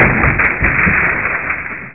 wood-01.mp3